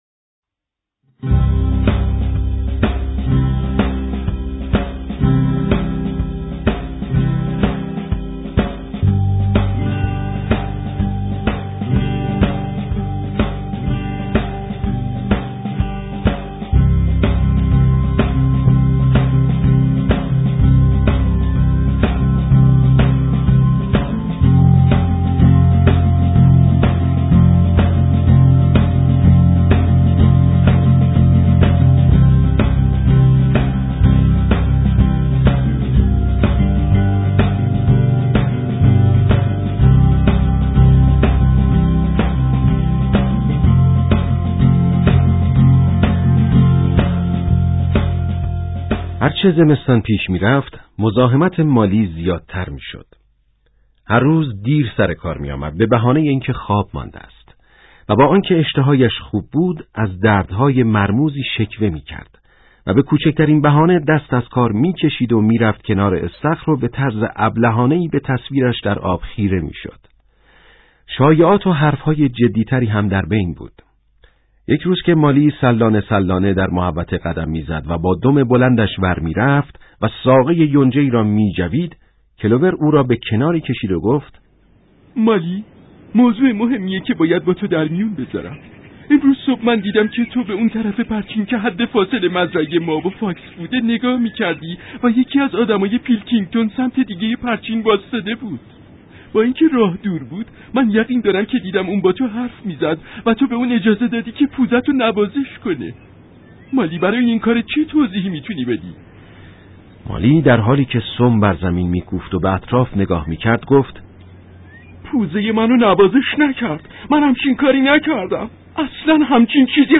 کتاب صوتی قلعه حیوانات اثر جورج اورول قسمت 5